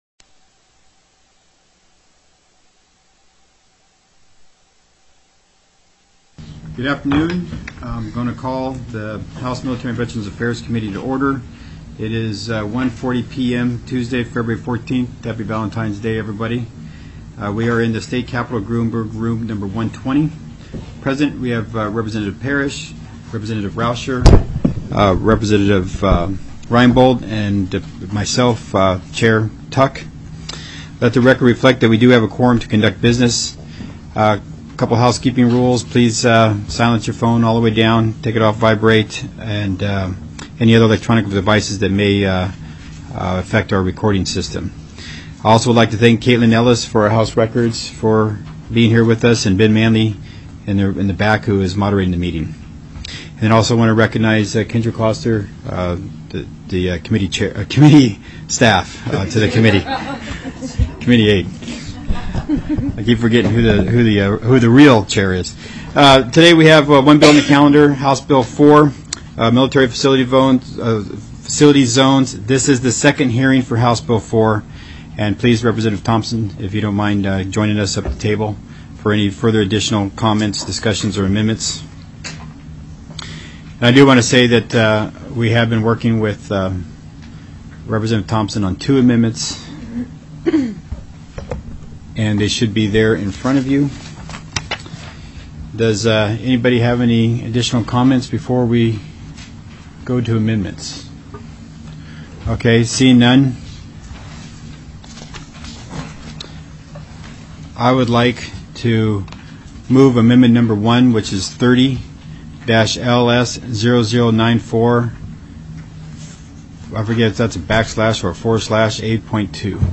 02/14/2017 01:30 PM House MILITARY & VETERANS' AFFAIRS
The audio recordings are captured by our records offices as the official record of the meeting and will have more accurate timestamps.
+= HB 4 MILITARY FACILITY ZONES TELECONFERENCED